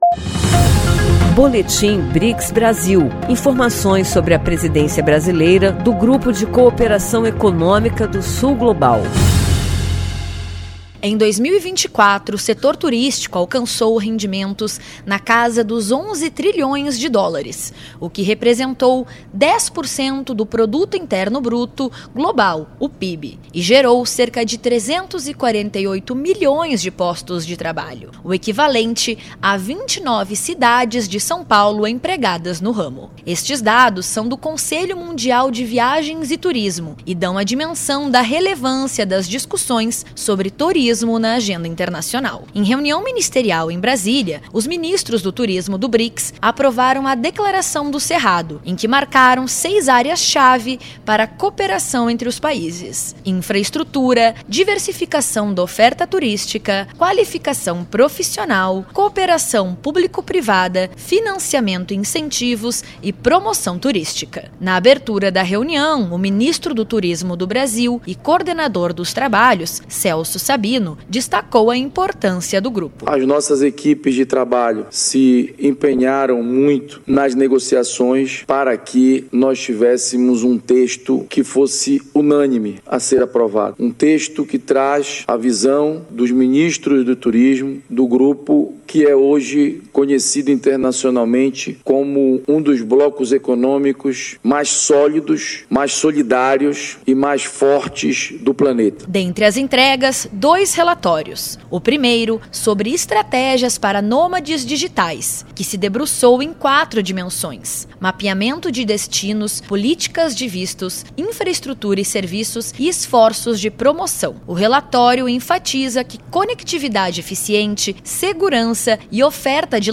Ministros do Turismo do BRICS aprovaram a Declaração do Cerrado, focando em cooperação em 6 áreas, como infraestrutura e turismo sustentável. Dois relatórios destacaram estratégias para nômades digitais e turismo regenerativo, visando crescimento econômico e ambiental. Ouça a reportagem e saiba mais.